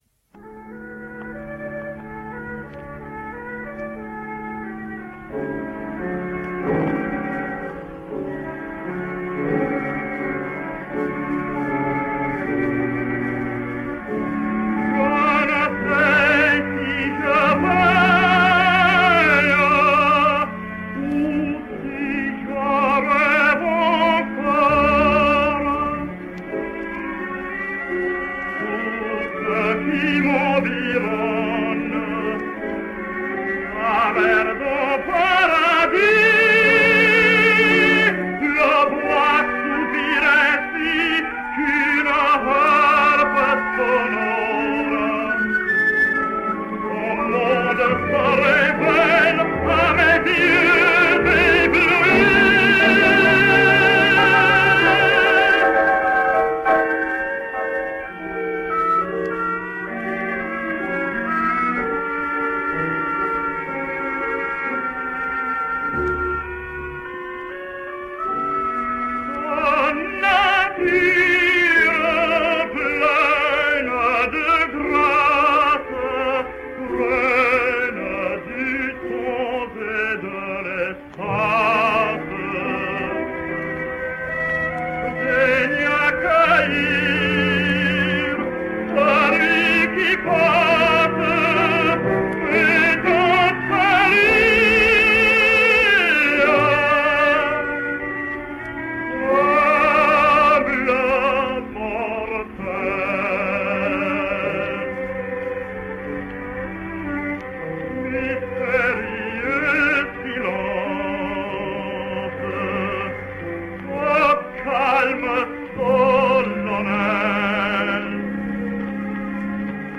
American Tenor.
An ample voice of rich quality won him success there, especially in the lyrical Spinto roles.